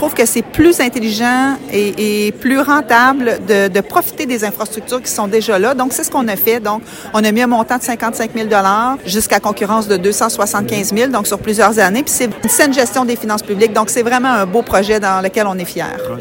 En entrevue, la mairesse de Nicolet, Geneviève Dubois, a apporté des précisions sur le rôle financier de la Ville alors que Québec a versé 4 M$ dans ce projet.